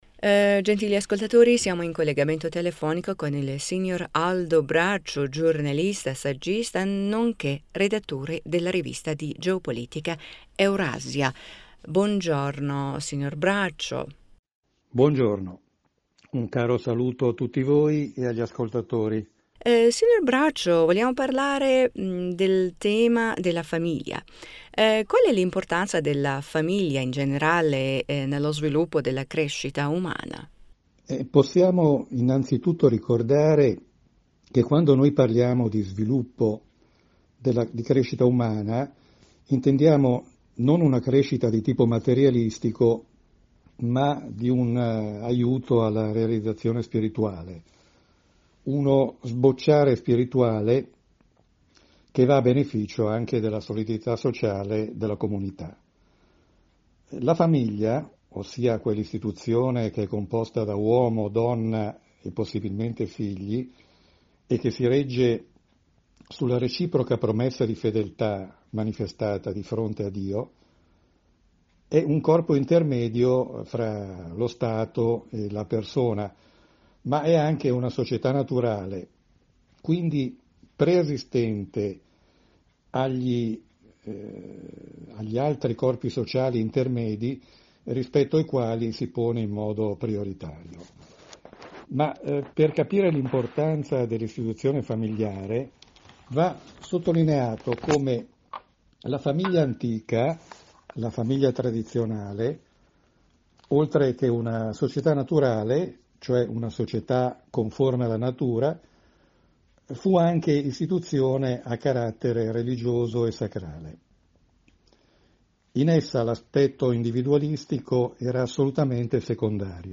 in un collegamento telefonico